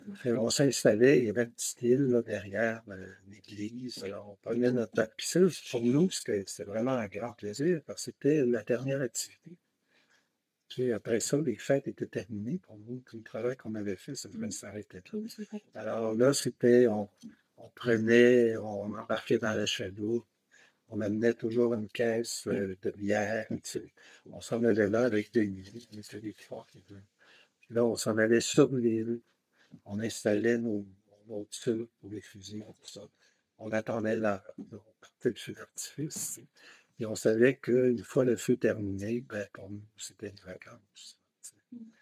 Audio excerpt: Interview